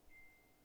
Electronic Bing Soft
beep bell bing ding electronic mode select tone sound effect free sound royalty free Sound Effects